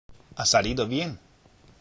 （ア　サリード　ビエン！）